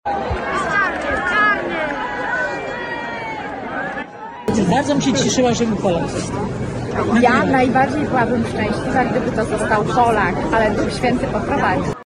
Emocje, jakie towarzyszą zgromadzonym wiernym na Placu Świętego Piotra w Rzymie, można usłyszeć na nagraniach